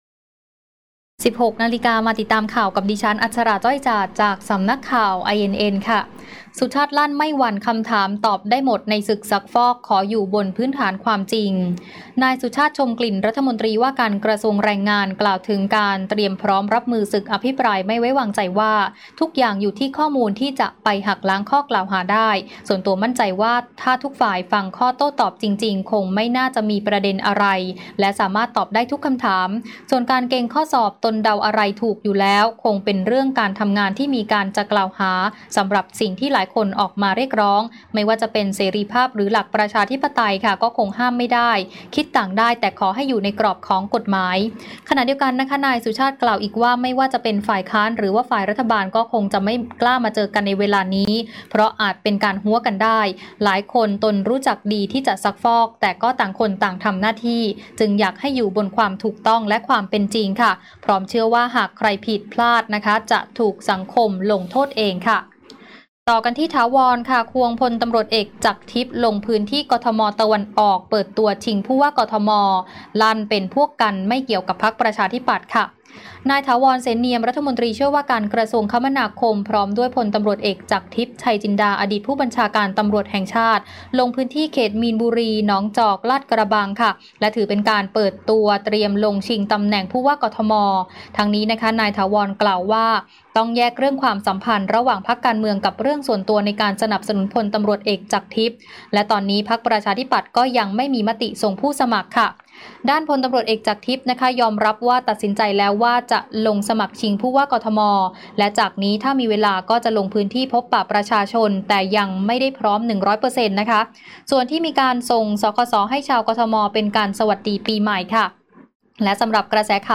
ข่าวต้นชั่วโมง 16.00 น.